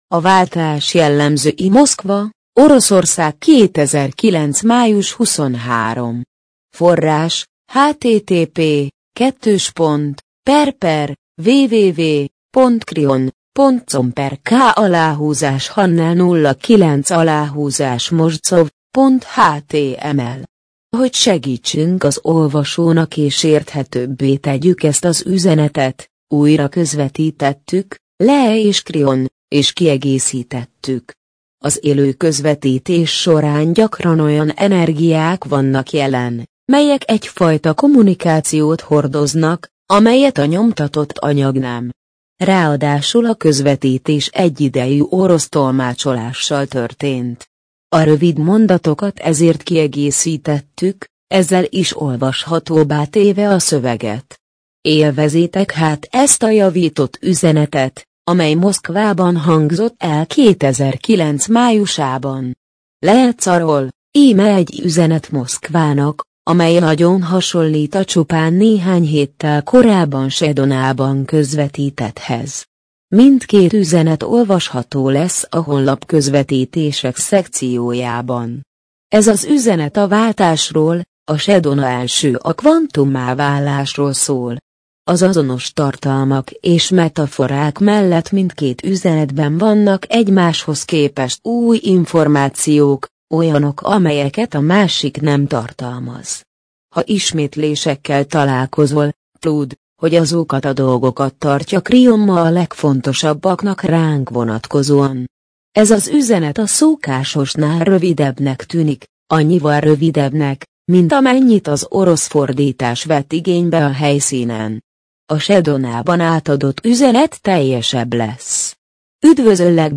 MP3 gépi felolvasás A váltás jellemzői A Váltás jellemzői Moszkva, Oroszország - 2009. május 23.